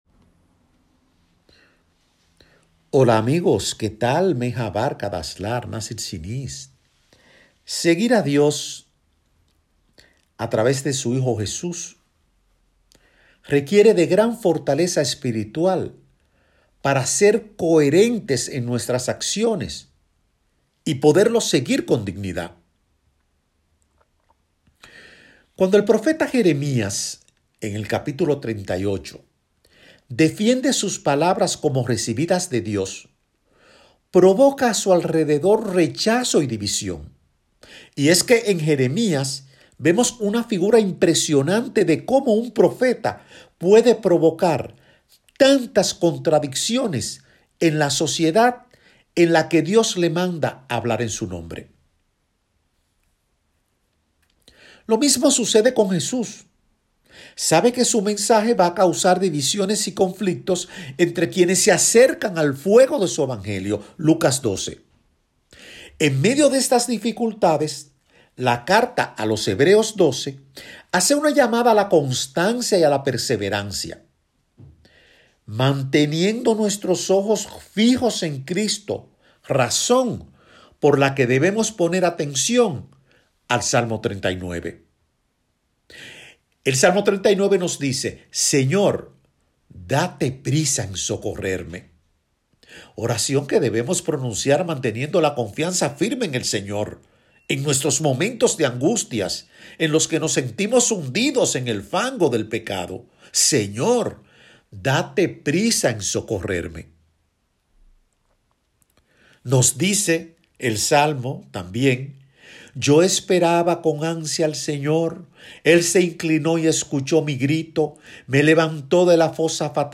prédica